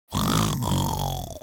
دانلود صدای شب 11 از ساعد نیوز با لینک مستقیم و کیفیت بالا
جلوه های صوتی
برچسب: دانلود آهنگ های افکت صوتی طبیعت و محیط دانلود آلبوم صدای شب از افکت صوتی طبیعت و محیط